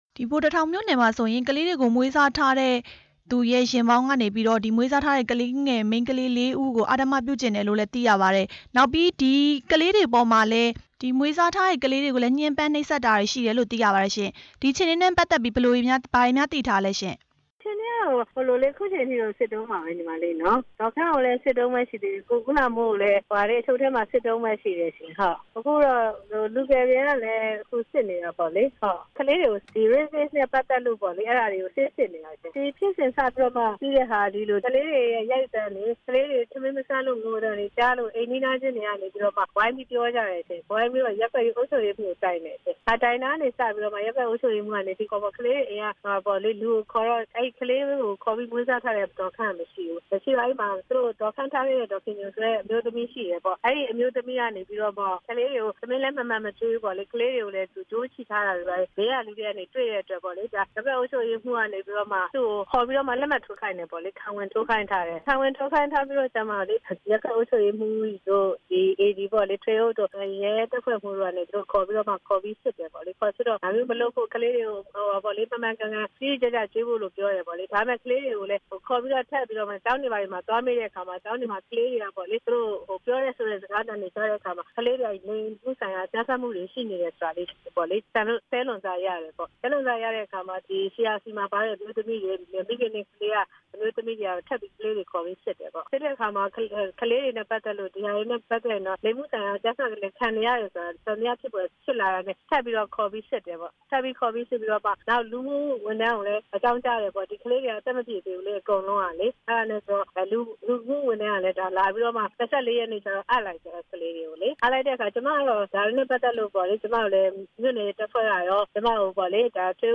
ပြည်သူ့လွှတ်တော် ကိုယ်စားလှယ် ဒေါ်မြင့်မြင့်စိုး နဲ့ မေးမြန်းချက်